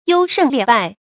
優勝劣敗 注音： ㄧㄡ ㄕㄥˋ ㄌㄧㄝ ˋ ㄅㄞˋ 讀音讀法： 意思解釋： 優：優良；與「劣」相對。